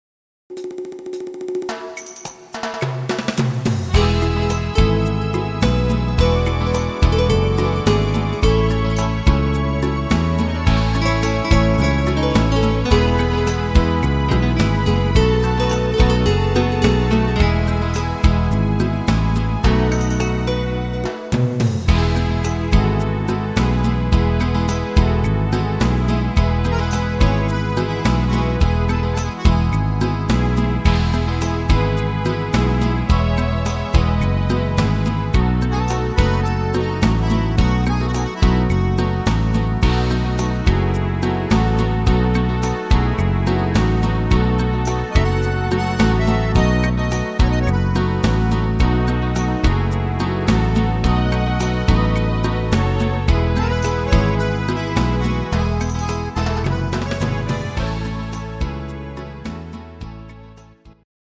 richtig guter Tanzrhythmus